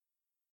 whoosh.ogg